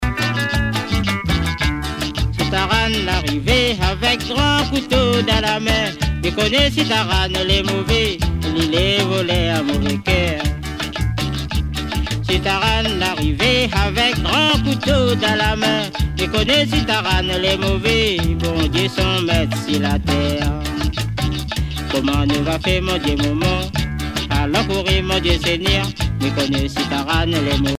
danse : séga
Pièce musicale éditée